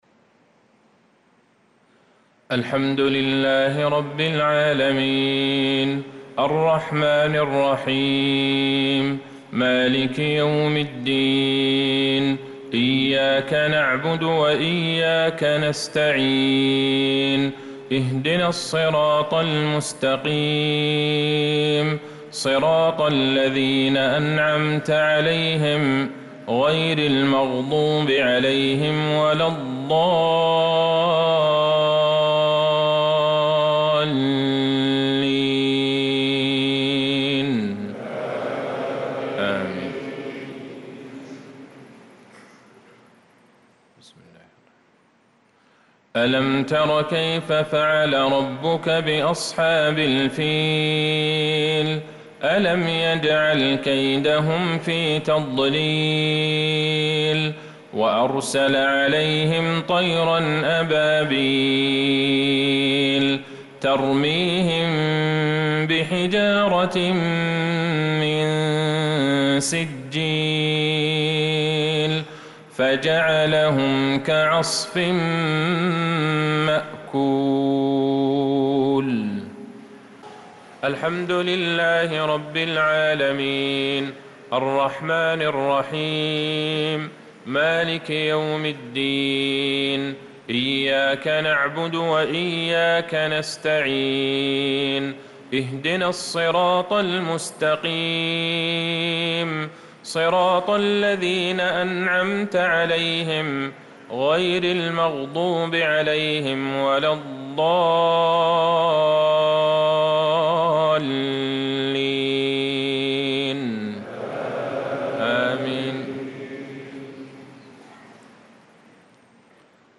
صلاة المغرب للقارئ عبدالله البعيجان 23 ذو الحجة 1445 هـ
تِلَاوَات الْحَرَمَيْن .